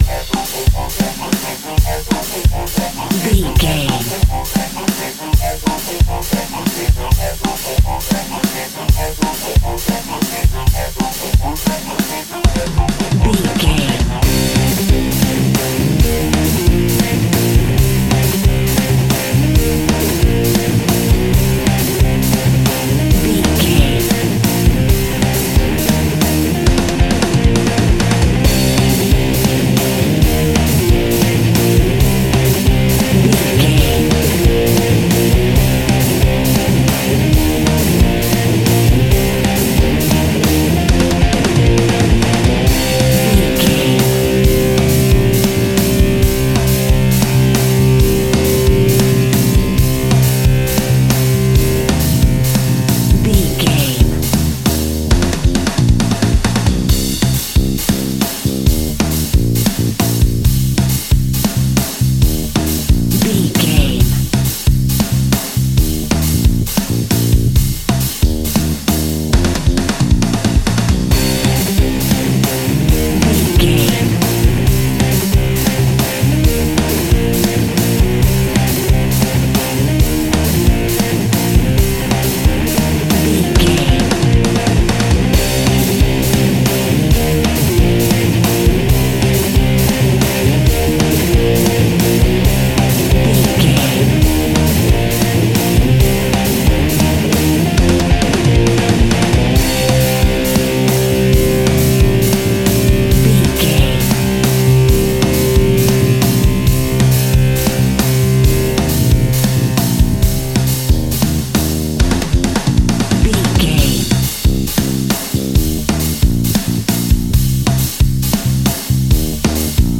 Epic / Action
Aeolian/Minor
hard rock
blues rock
distortion
Rock Bass
heavy drums
distorted guitars
hammond organ